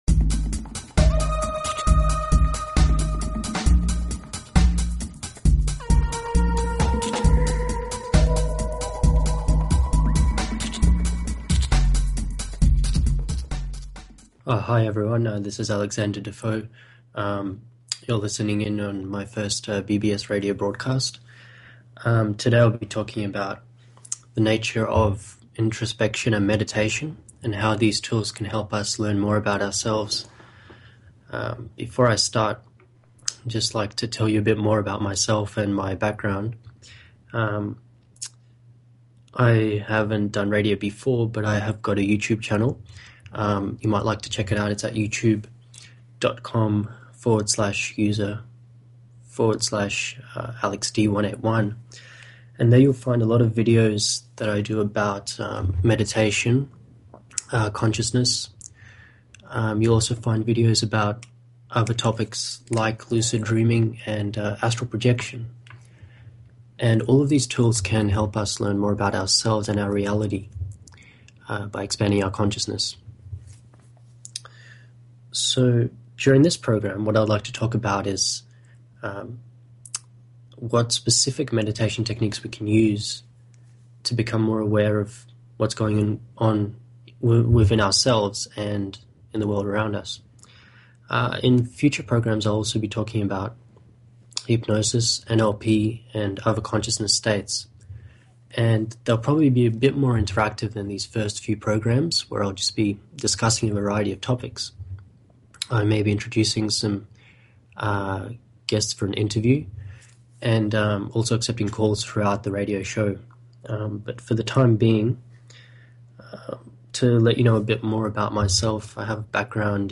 Talk Show Episode, Audio Podcast, Awaken_Your_Higher_Mind and Courtesy of BBS Radio on , show guests , about , categorized as